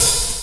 HH 27.wav